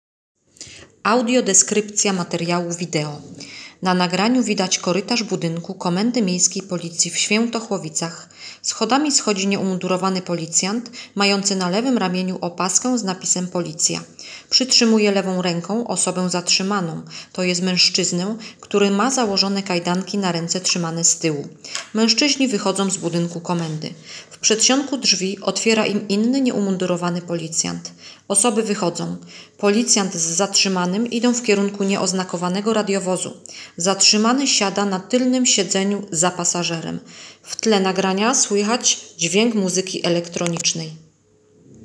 Nagranie audio audiodeskrypcja materiału wideo